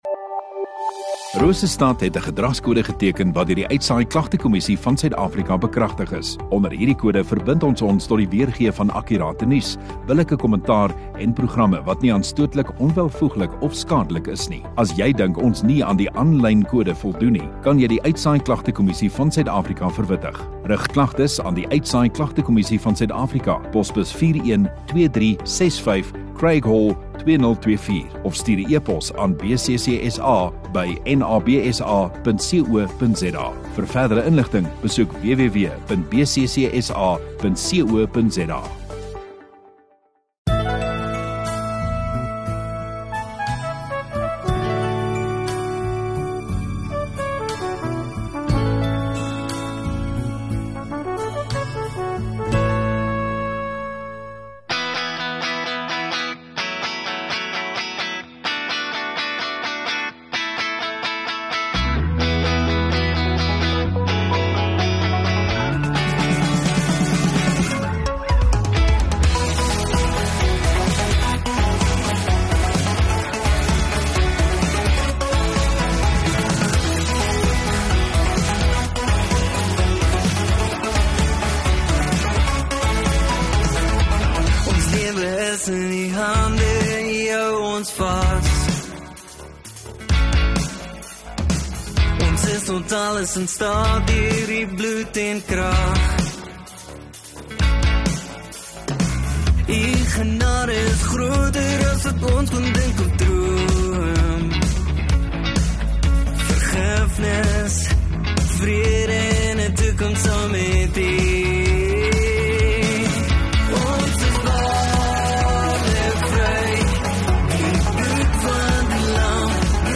16 Dec GELOFTEDAG Maandagoggend Erediens